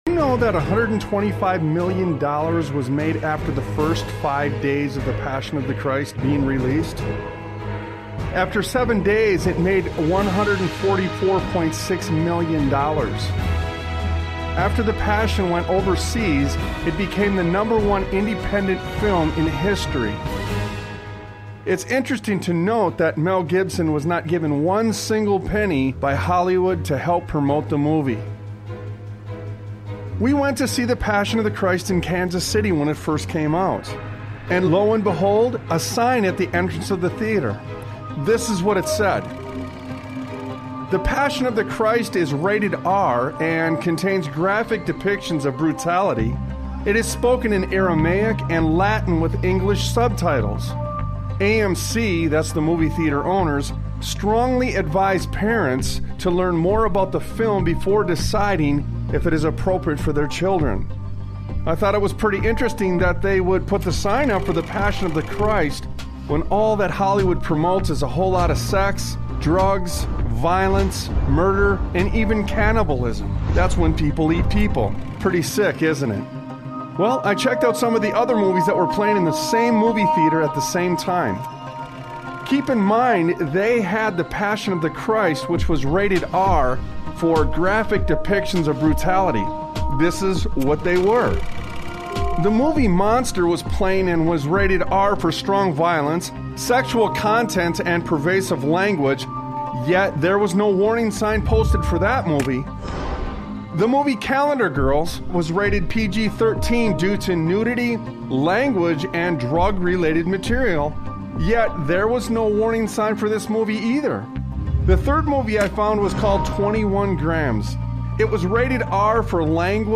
Talk Show Episode, Audio Podcast, Sons of Liberty Radio and When The Rats Scurry on , show guests , about When The Rats Scurry, categorized as Education,History,Military,News,Politics & Government,Religion,Christianity,Society and Culture,Theory & Conspiracy